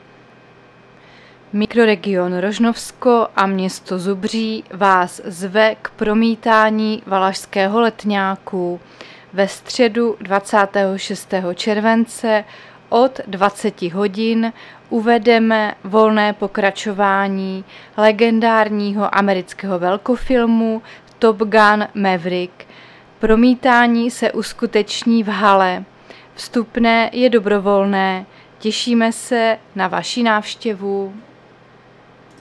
Záznam hlášení místního rozhlasu 26.7.2023